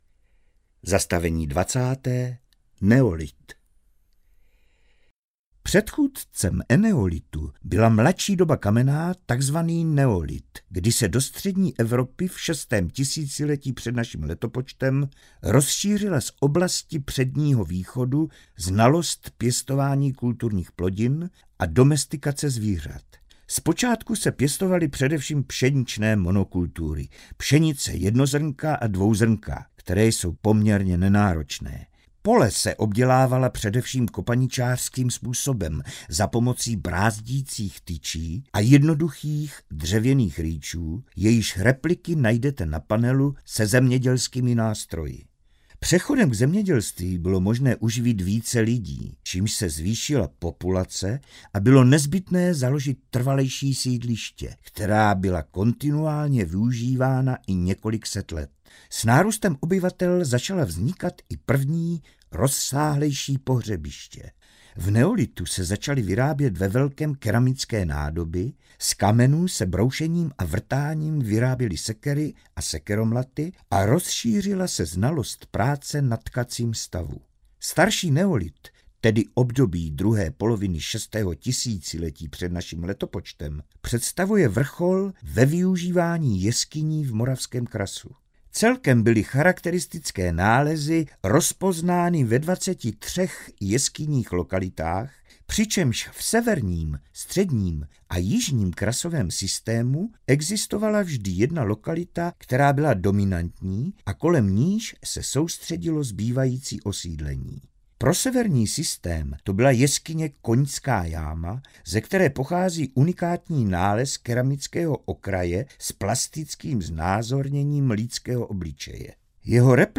Audio průvodce